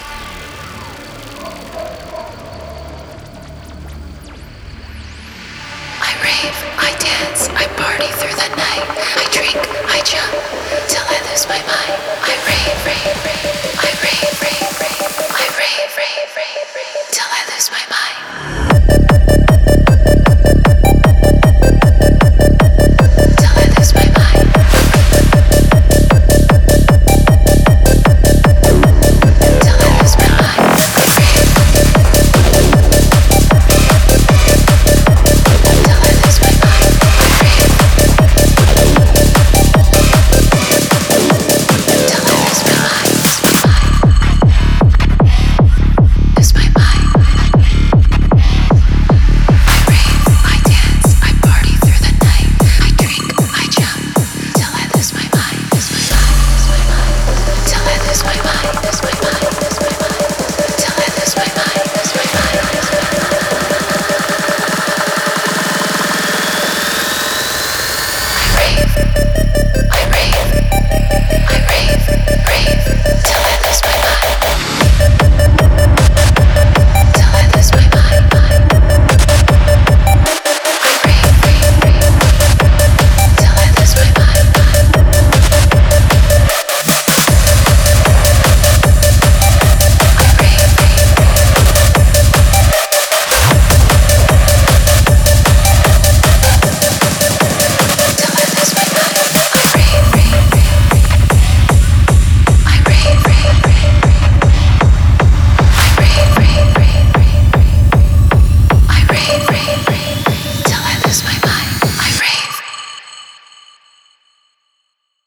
Музыка для тренировок
ритмичная музыка для спорта